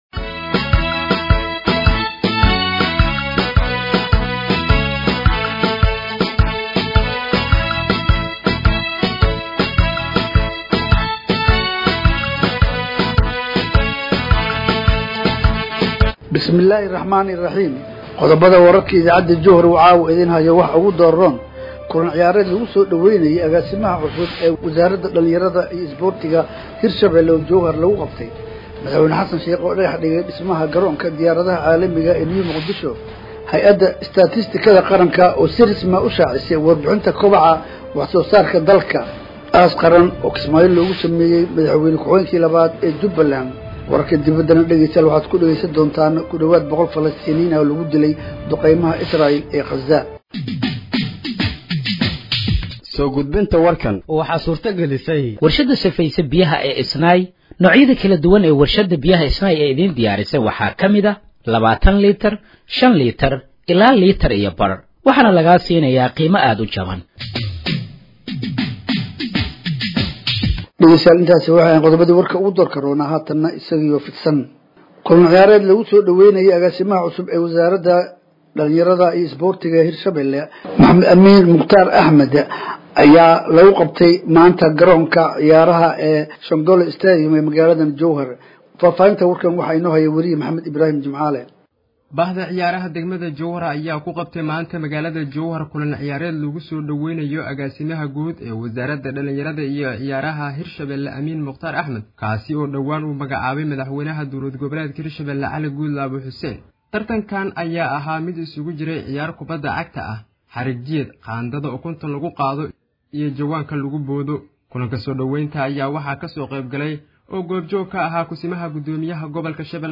Dhageeyso Warka Habeenimo ee Radiojowhar 29/06/2025